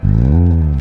Sound Buttons: Sound Buttons View : Bonk_2
bonk_JB7gaZQ.mp3